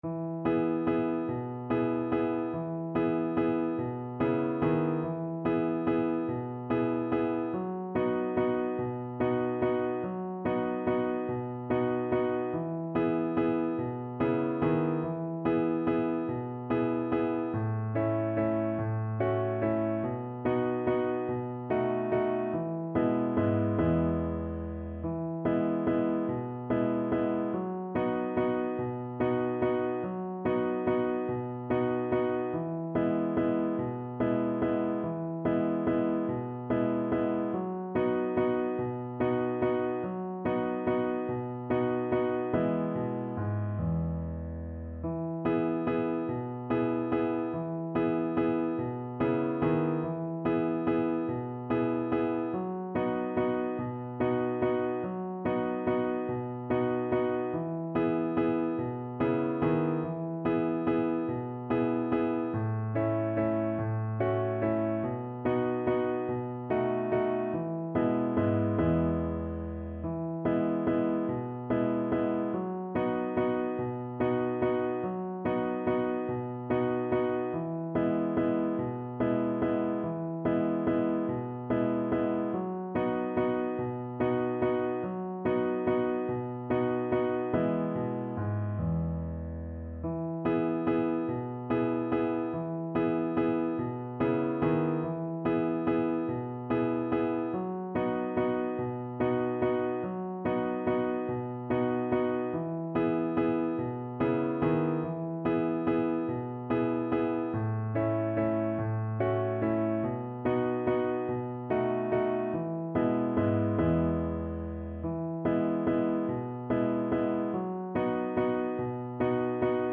3/8 (View more 3/8 Music)
Steady one in a bar .=c.48
Traditional (View more Traditional Voice Music)